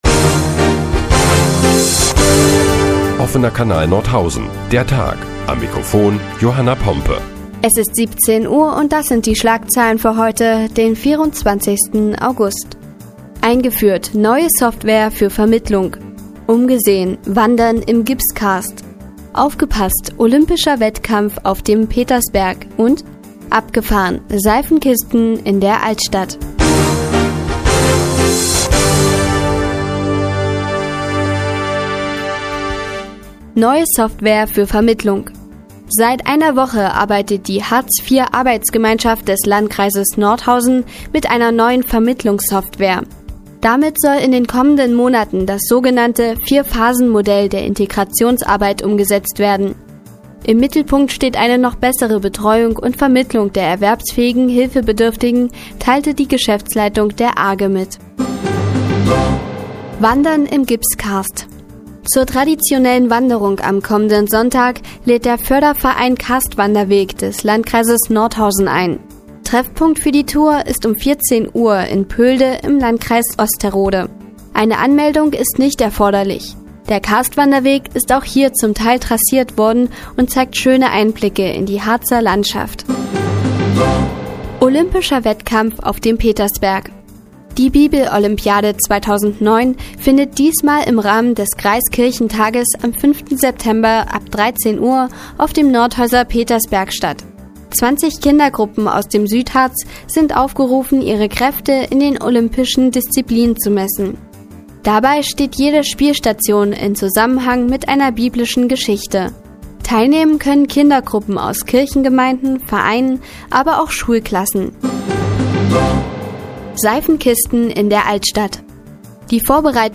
Die tägliche Nachrichtensendung des OKN ist nun auch in der nnz zu hören. Heute geht es unter anderem um Seifenkisten in der Altstadt und olympischer Wettkampf auf dem Petersberg.